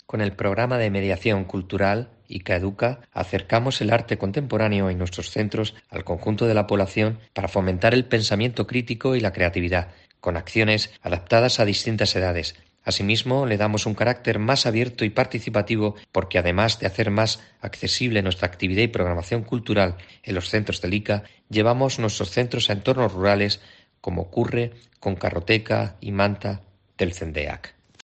Manuel Cebrián, Director del Instituto de las Industrias Culturales y las Artes